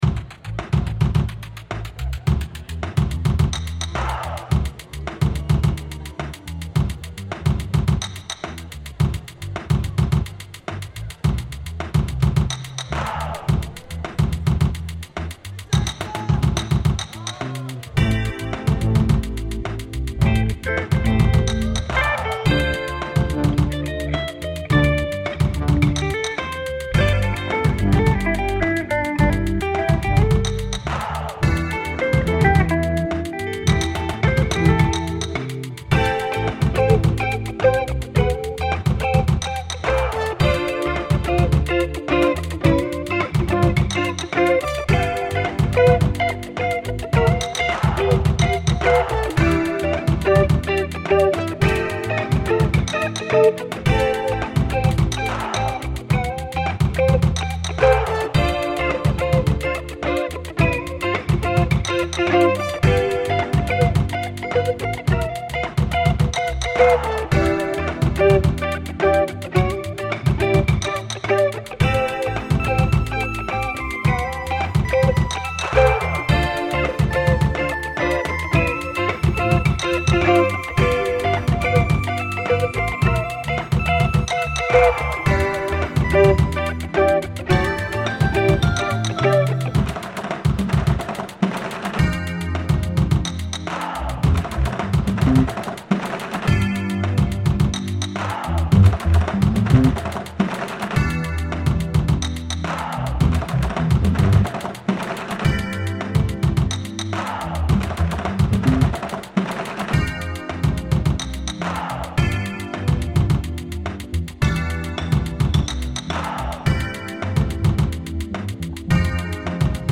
Taiko drumming in Ferrara reimagined